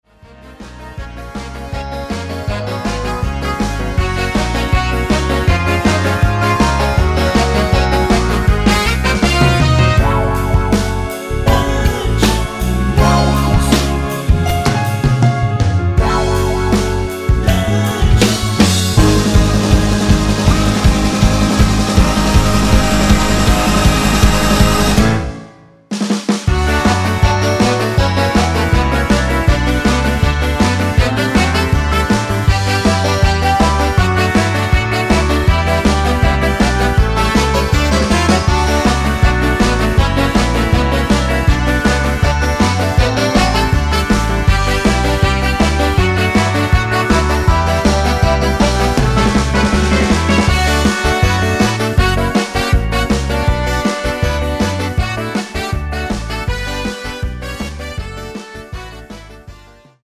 중간 코러스 포함된(-1) MR 입니다.(미리듣기 참조)
Db
앞부분30초, 뒷부분30초씩 편집해서 올려 드리고 있습니다.
중간에 음이 끈어지고 다시 나오는 이유는
곡명 옆 (-1)은 반음 내림, (+1)은 반음 올림 입니다.